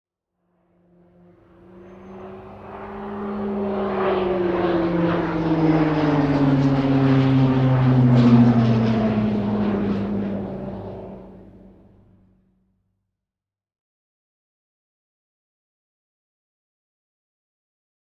Airplane Catalina overhead propeller